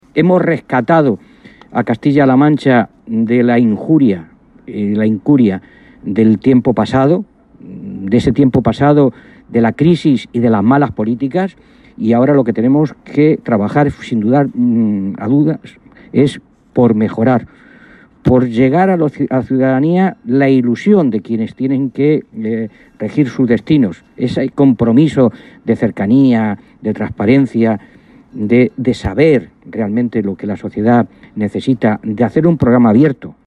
Maestre realizaba estas declaraciones en un receso de la reunión que han mantenido, esta mañana en Toledo, más de un centenar de representantes socialistas, expertos en distintas áreas, para dar el “pistoletazo de salida” en la elaboración del programa electoral con el que el PSCM-PSOE concurrirá a las próximas elecciones autonómicas.
Cortes de audio de la rueda de prensa